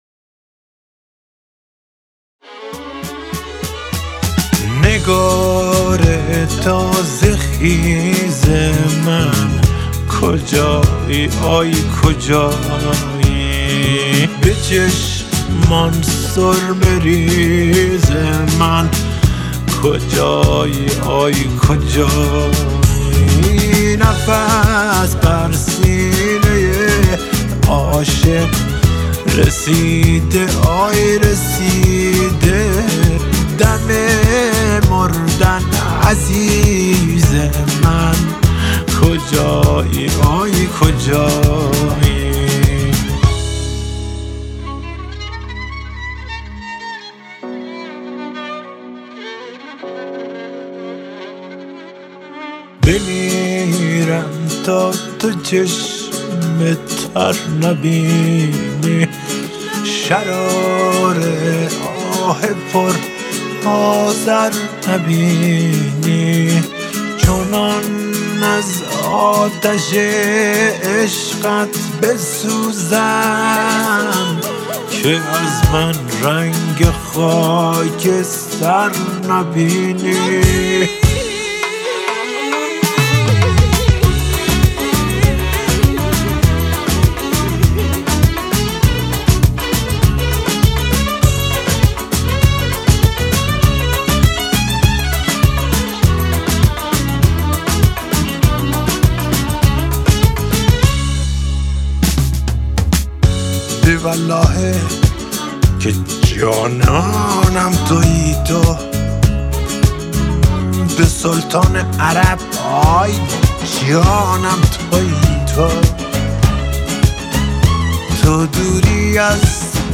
دانلود آهنگ غمگین با متن کامل